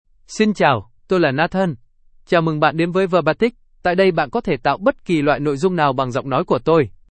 NathanMale Vietnamese AI voice
Voice sample
Male
Nathan delivers clear pronunciation with authentic Vietnam Vietnamese intonation, making your content sound professionally produced.